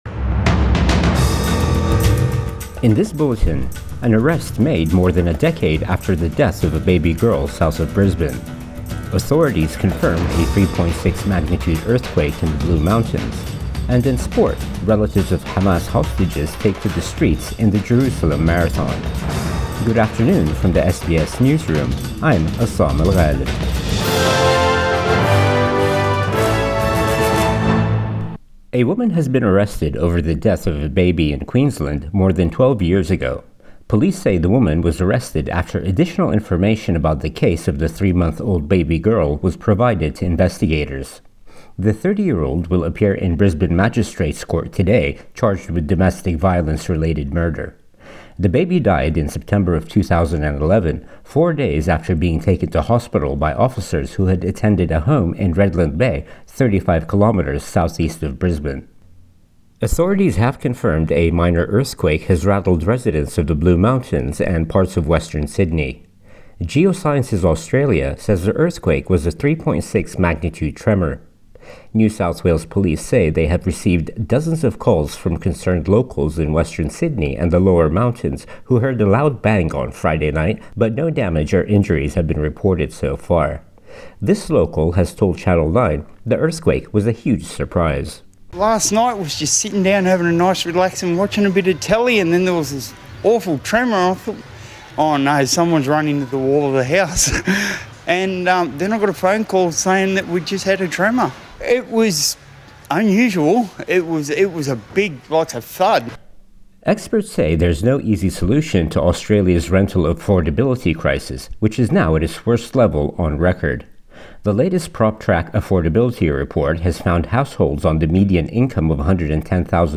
Midday News Bulletin 9 March 2024